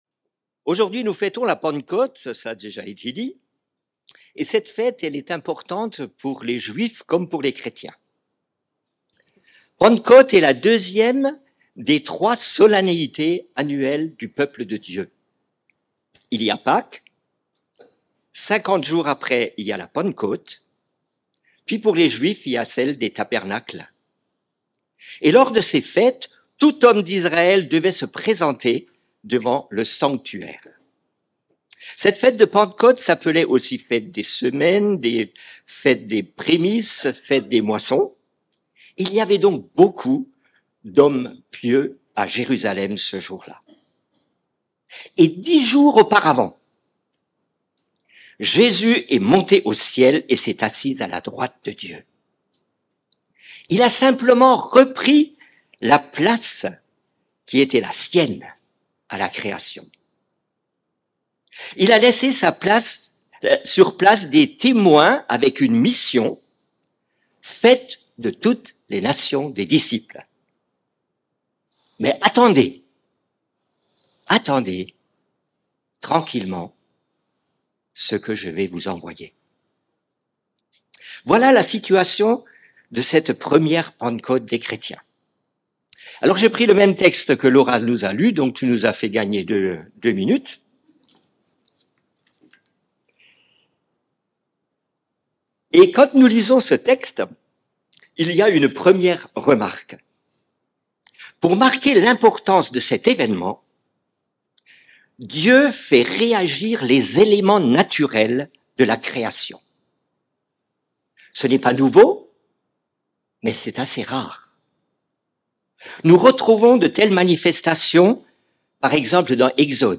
Culte hebdomadaire - EEBS - Église Évangélique Baptiste de Seloncourt